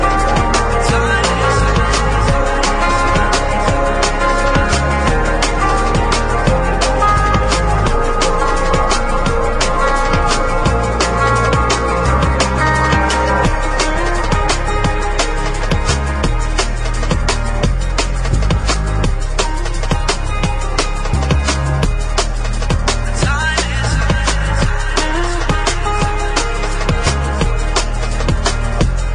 TOP >Vinyl >Drum & Bass / Jungle
TOP > Deep / Liquid